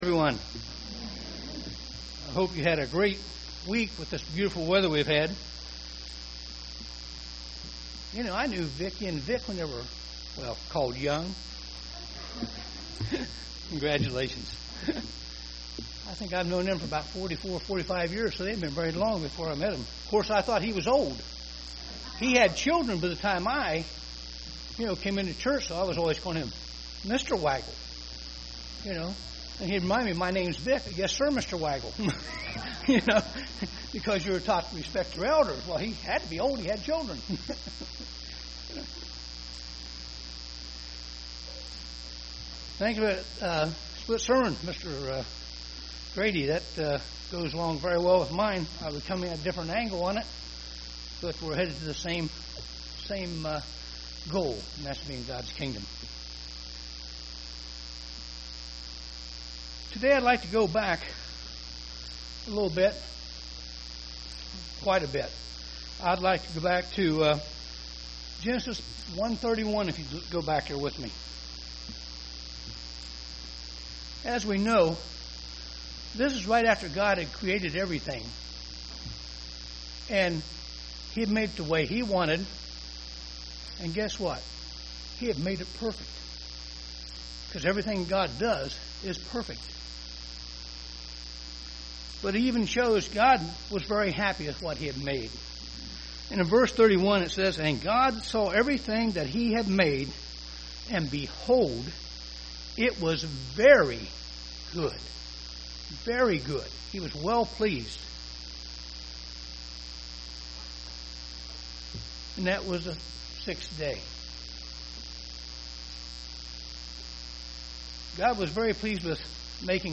Print Overview of early mandkind and today UCG Sermon Studying the bible?
Given in Dayton, OH